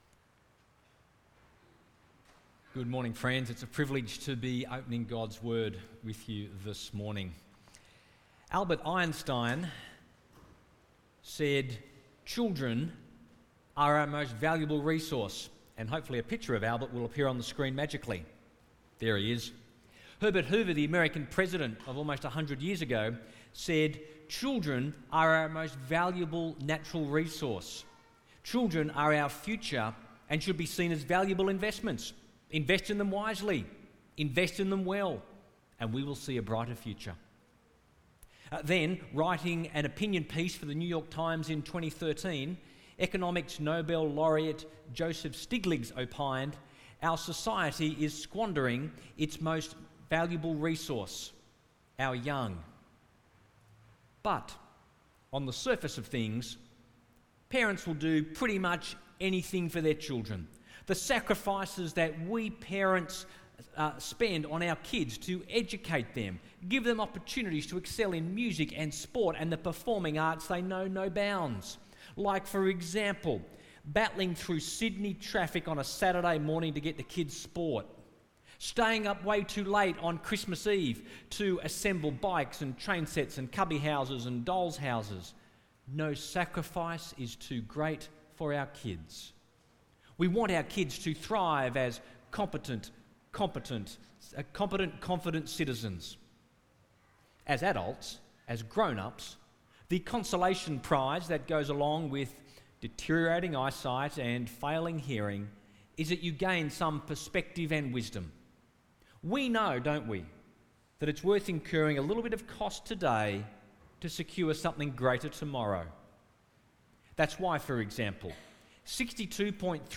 Sunday sermon
from St John’s Anglican Cathedral Parramatta.